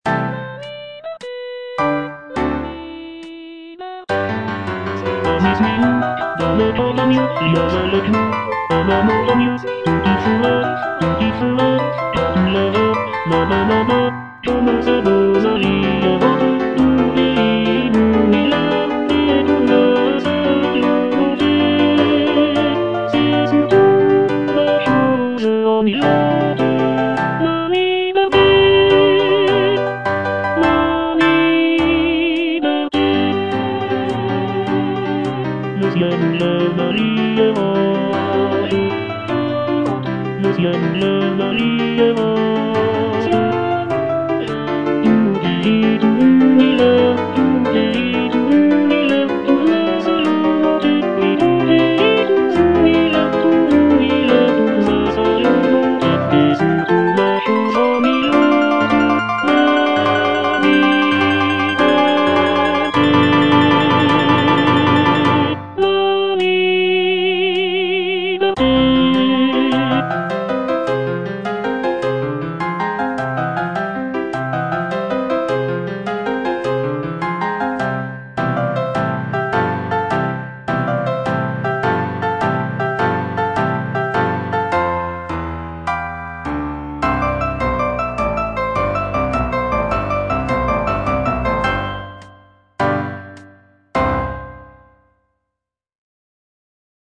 G. BIZET - CHOIRS FROM "CARMEN" Suis-nous à travers la campagne (tenor II) (Voice with metronome) Ads stop: auto-stop